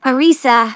synthetic-wakewords
ovos-tts-plugin-deepponies_Starlight_en.wav